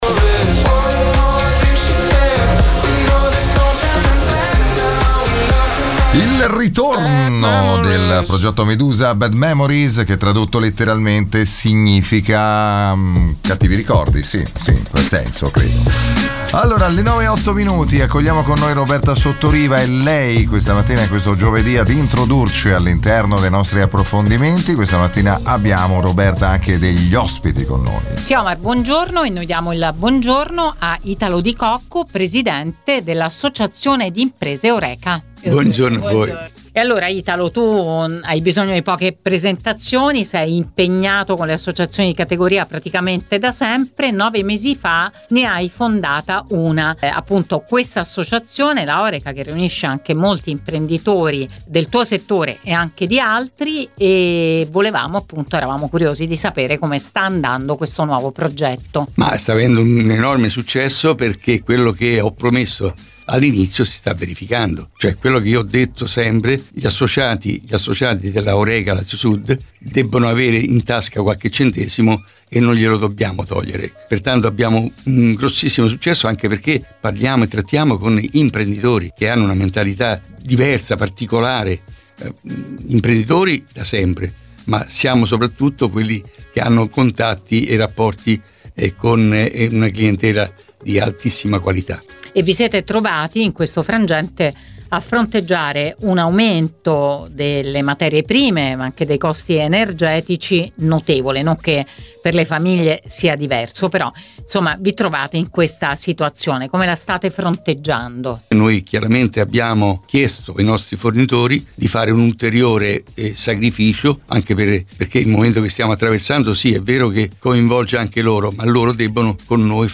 L’intervista su Radio Immagine